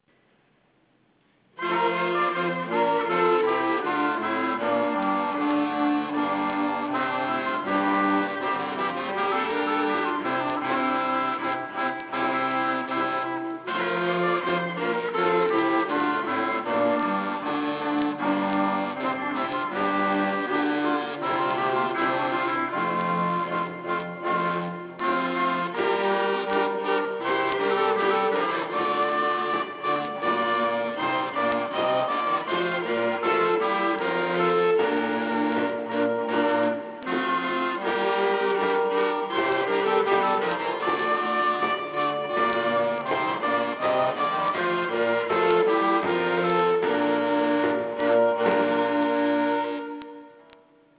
Gabrieli wrote Sonata Pian' e Forte for two contrasting groups of instruments. In each group, the three lower instruments are Sackbuts (the predecessor to the modern trombone).
A Consort of Players